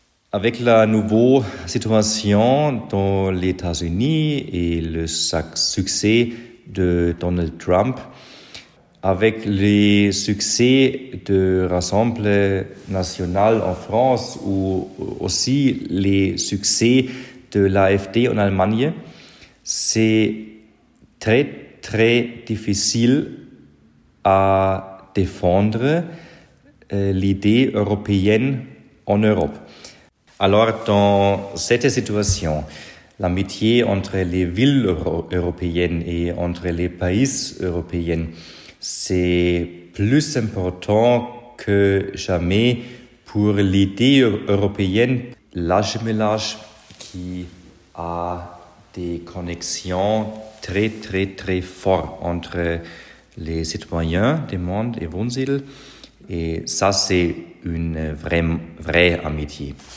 Nous remercions Nicolas Lahovnik pour ses réponses dans un français irréprochable.